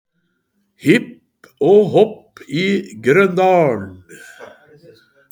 Skjærsklyd.mp3